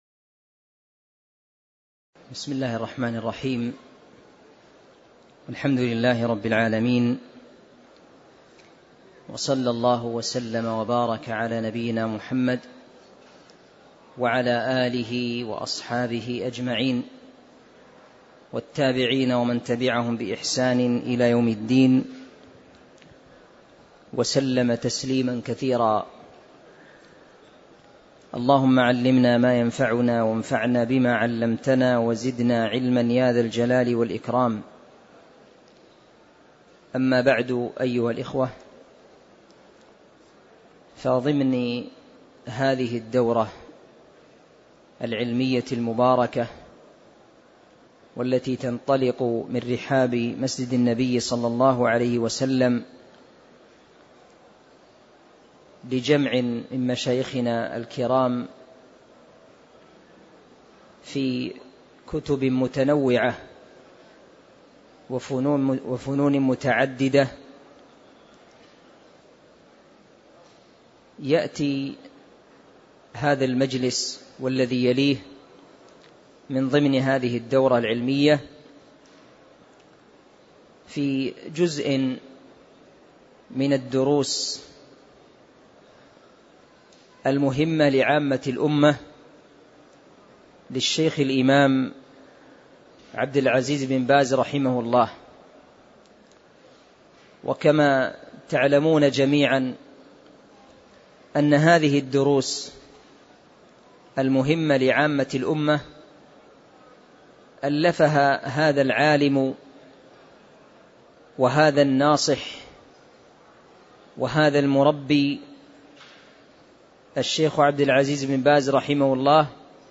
الدرس الخامس عشر التحلي بالأخلاق المشروعة (01)
المكان: المسجد النبوي